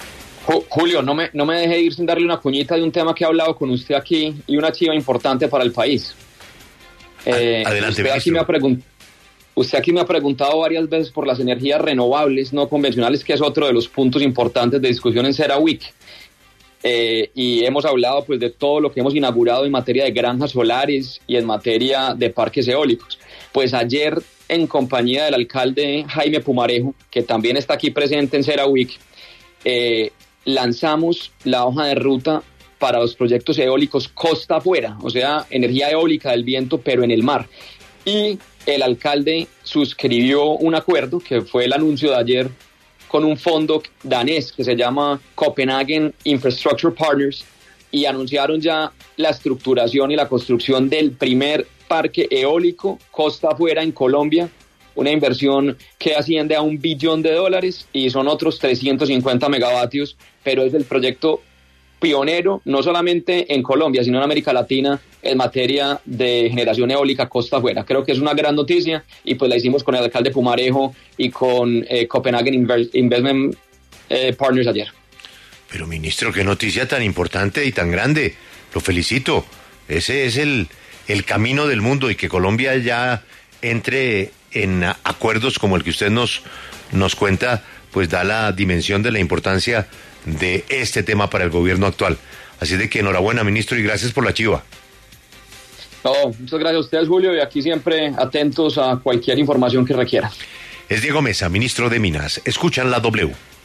Lo invitamos a que escuche la entrevista completa al ministro de Minas y Energía, Diego Mesa, en La W: